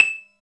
39 MARIMBA-R.wav